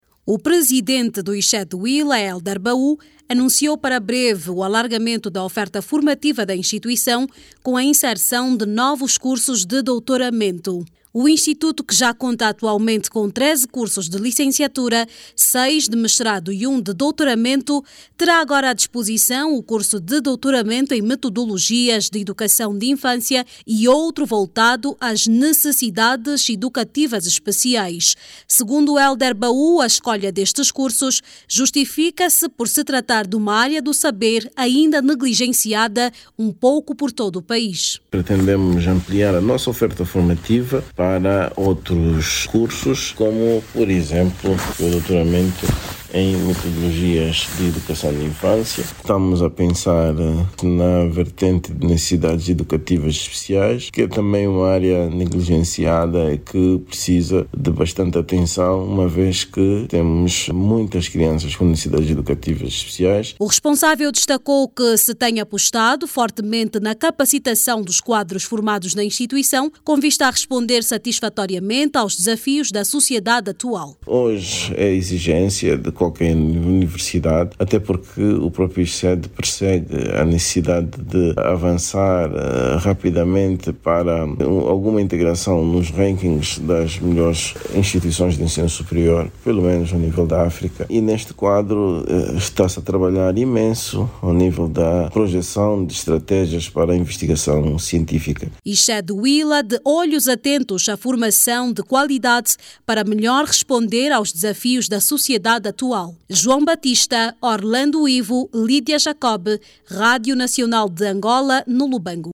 O ISCED Huíla, vai neste ano lectivo alargar a sua oferta formativa com a inserção de cursos de licenciatura e doutoramento em edução de infância e necessidades educativas especiais. A Direcção do ISCED Huíla, justifica a sua decisão ao número elevado de pessoas com necessidades especiais e escassez de especialista em educação de infância. Clique no áudio abaixo e ouça a reportagem